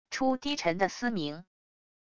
出低沉的嘶鸣wav音频